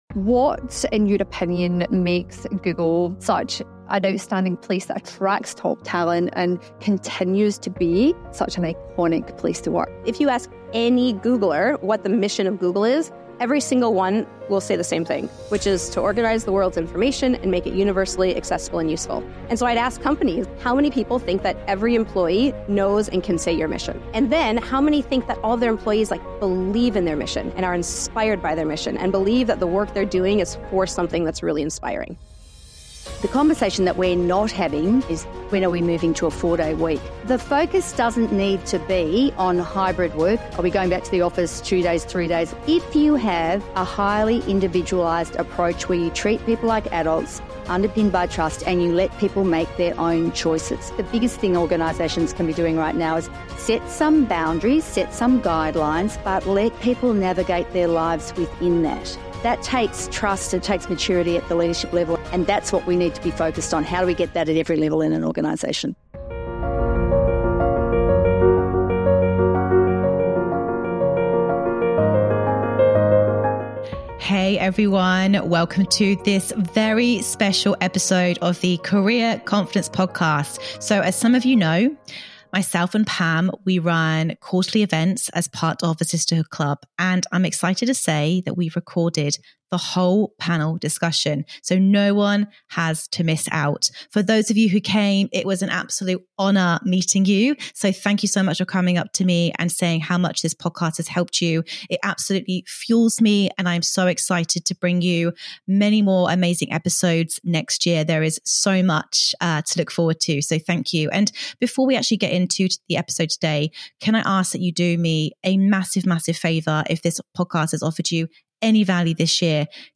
This week we are sharing the audio from our recent Sisterhood Social event live in Melbourne Australia.